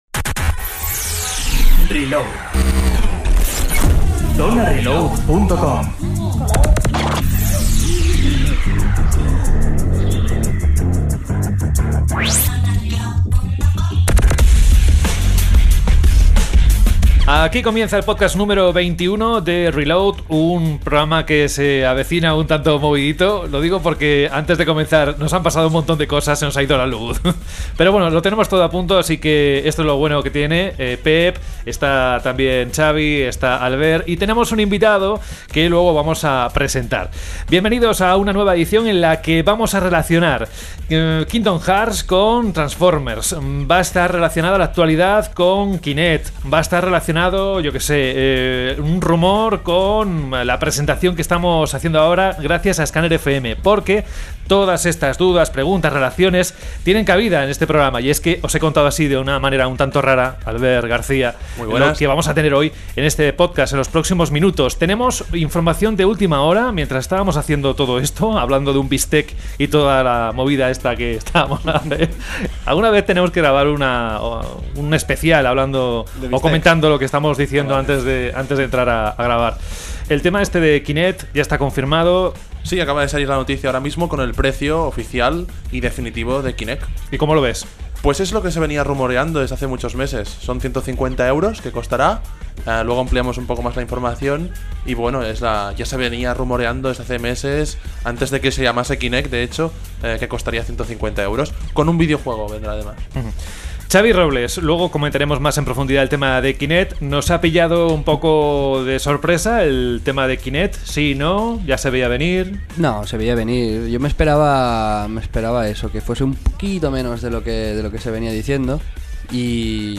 Reload se graba en los estudios de Scanner FM , una radio alternativa que seguro que os gustará y que podéis escuchar por Internet. Guión del programa En el primer round hablamos sobre las novedades en los diferentes servicios de descarga digital (Xbox Live Arcade, PlayStation Network, WiiWare, App Store, etc.) para este verano.